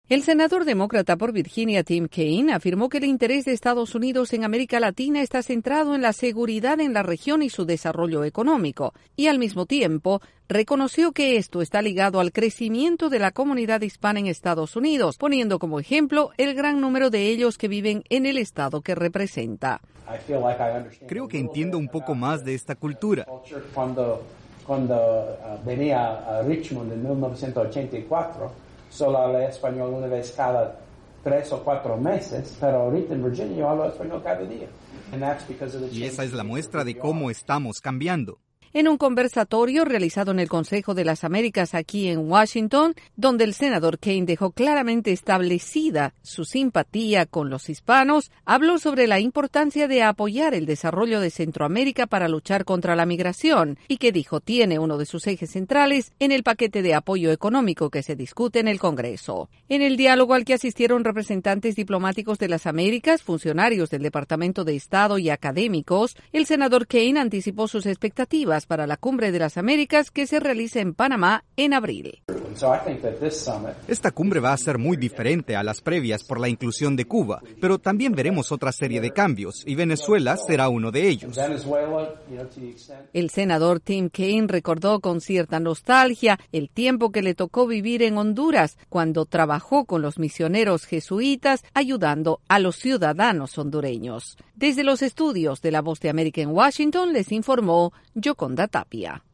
El senador demócrata por Virginia, Tim Kaine, destaca la importancia de Latinoamérica en la agenda del Congreso de Estados Unidos. El informe desde la Voz de América en Washington DC